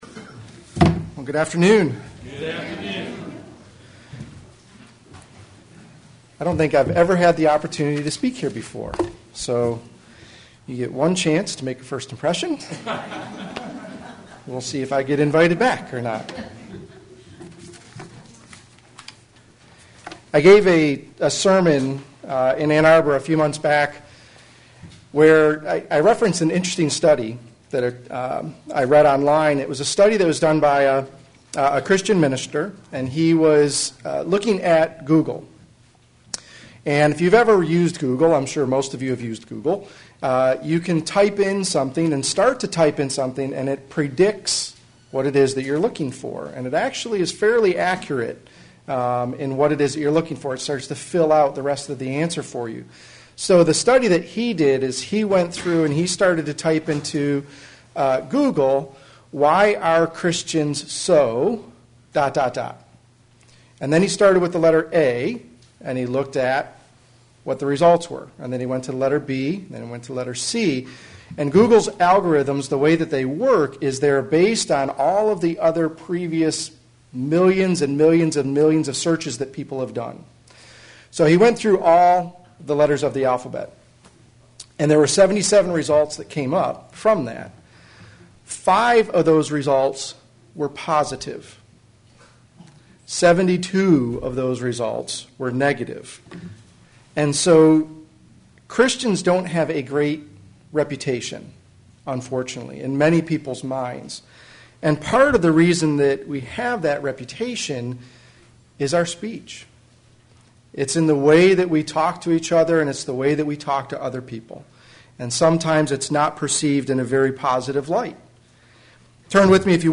Given in Flint, MI
They are more important than most people believe. sermon Studying the bible?